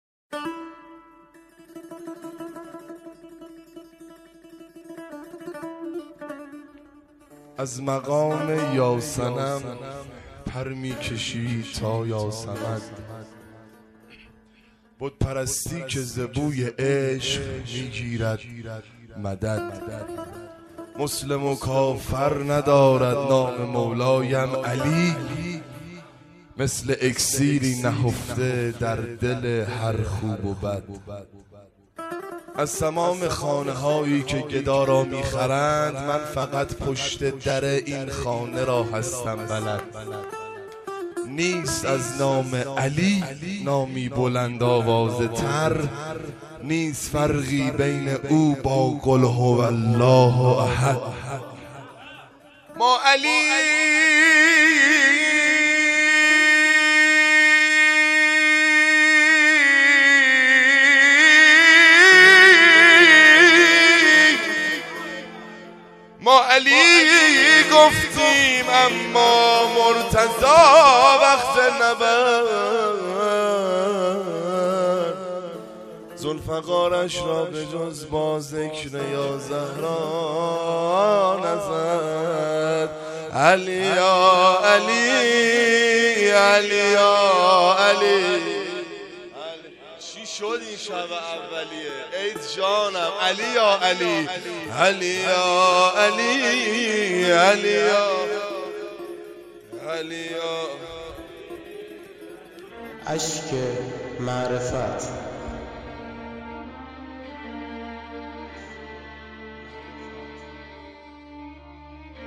شب اول محرم 1396 - شعرخوانی